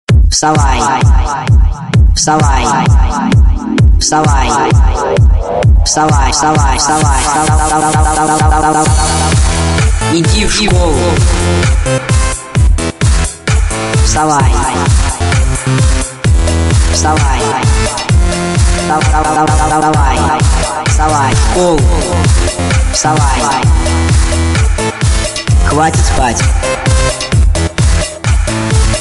Звуки звонка, будильника
Звук для будильника Вставай в школу пора